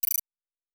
pgs/Assets/Audio/Sci-Fi Sounds/Interface/Data 18.wav at master